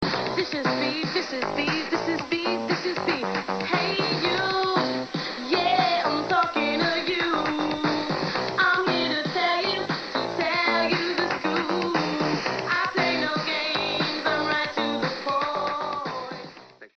make cameo appearences singing early dance tracks.